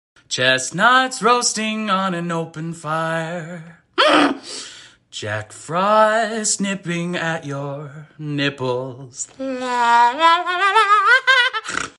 OOPS🤪 sound effects free download
You Just Search Sound Effects And Download. tiktok sound effects funny Download Sound Effect Home